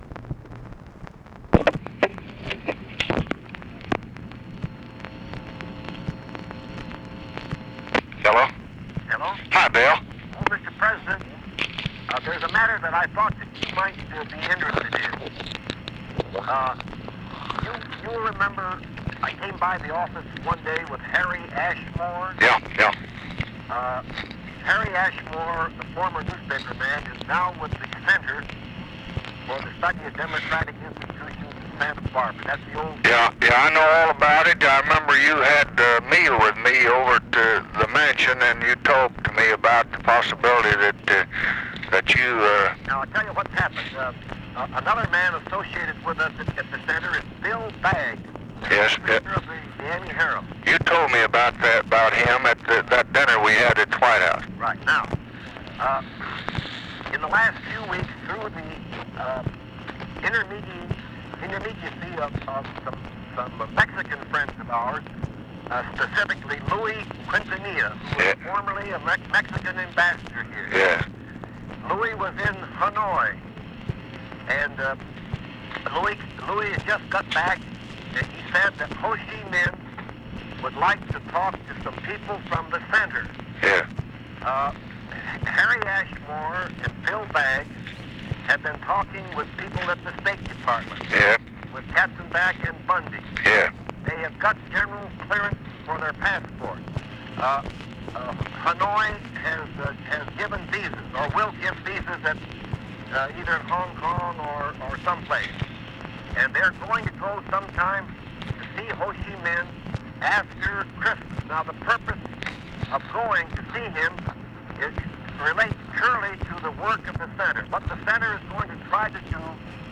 Conversation with WILLIAM O. DOUGLAS, December 8, 1966
Secret White House Tapes